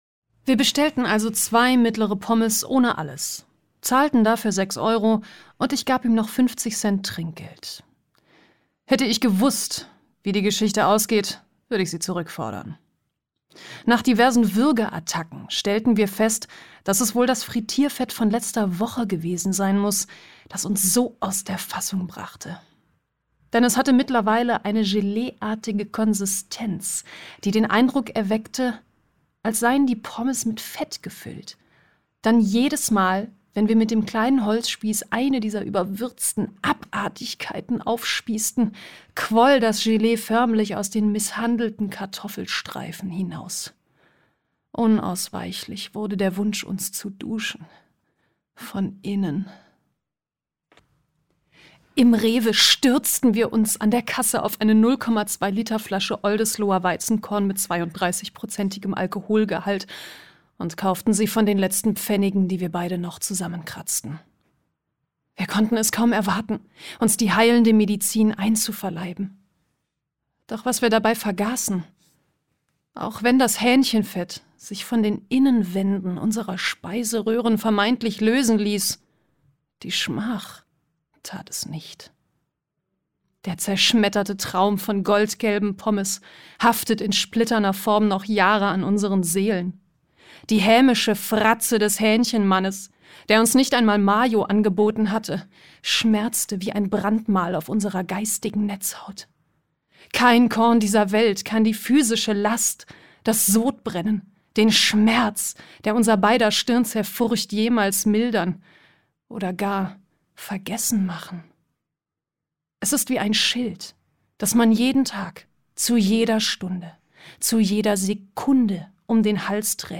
Comedy Monolog